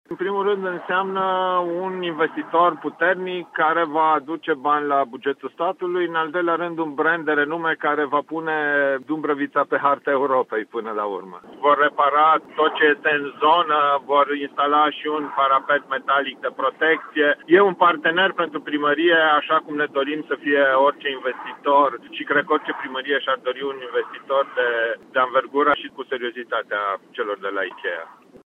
Deschiderea magazinului Ikea va avea loc în data de 8 iunie pe un teren care aparține de comuna Dumbrăvița. Suma de aproximativ 60 de milioane de euro transformă acest concern în mai mare investitor de până acum din Dumbrăvița, spune primarul Horia Bugărin.